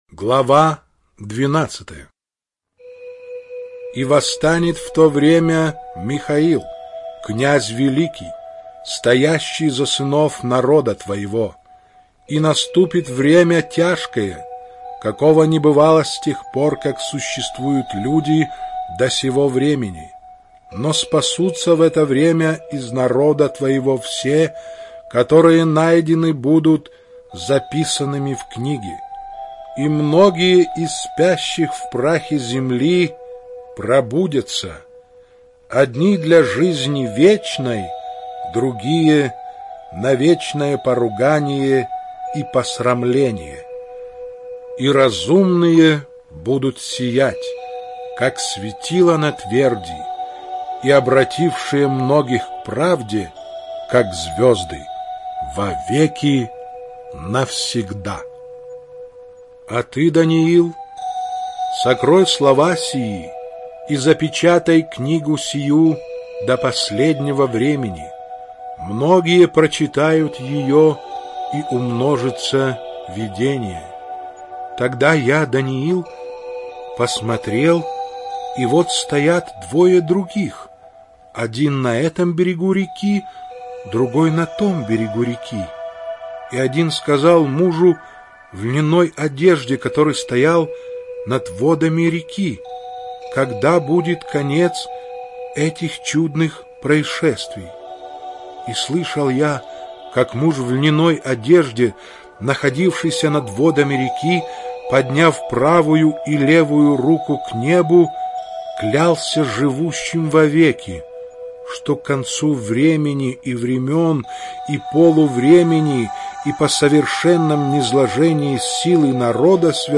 Аудио книга пророка Даниила на mp3
Чтение сопровождается оригинальной музыкой и стерео-эффектами